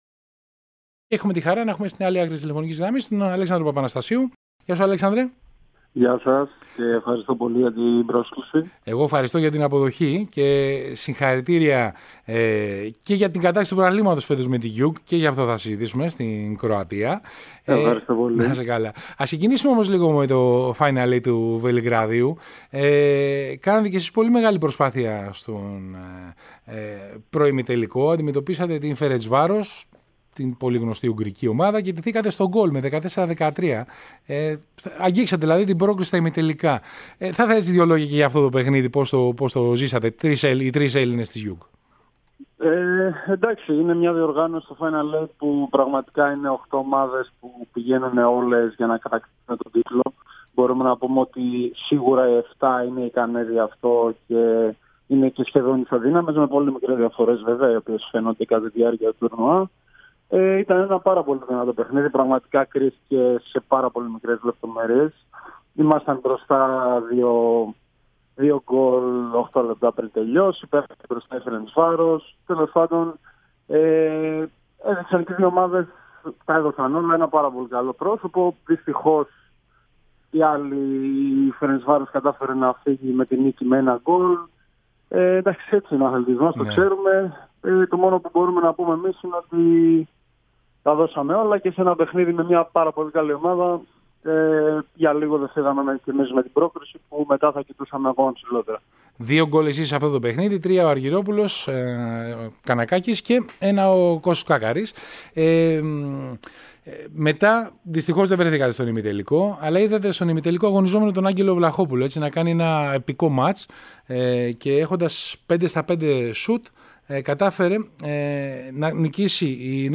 Σε συνέντευξη